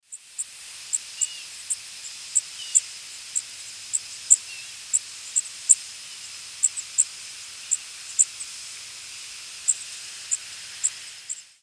Blackburnian Warbler diurnal flight calls
Diurnal calling sequences: